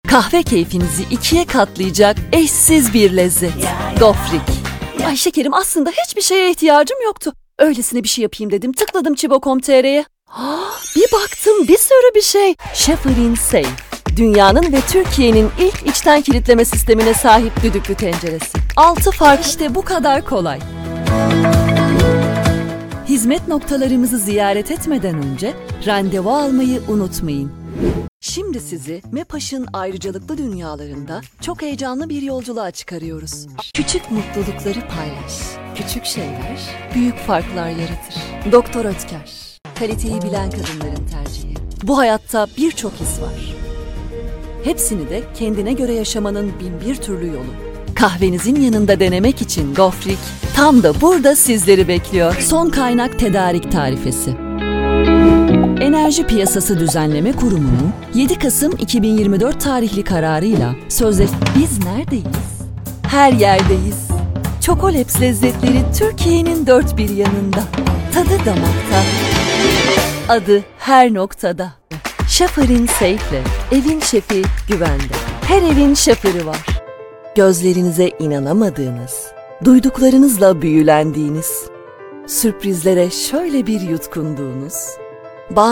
Profundo, Natural, Cool, Cálida, Empresarial
Audioguía
She has her own professional home studio.